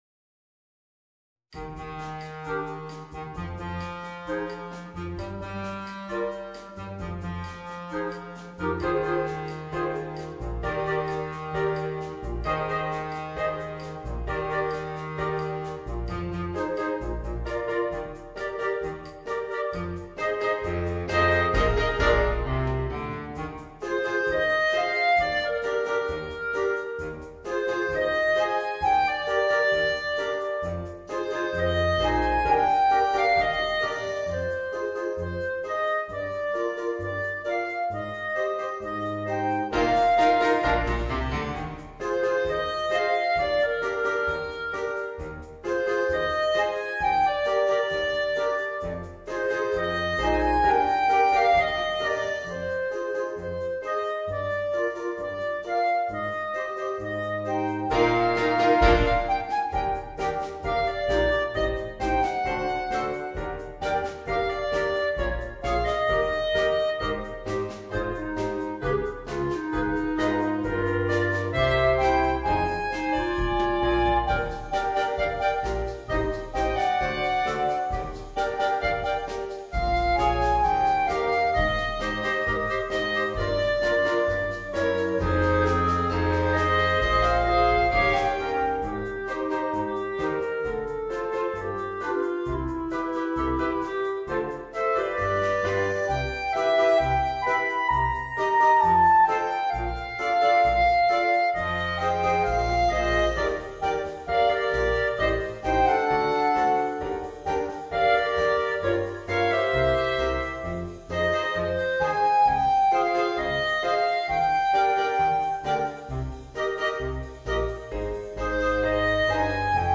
Voicing: 4 Clarinets and Piano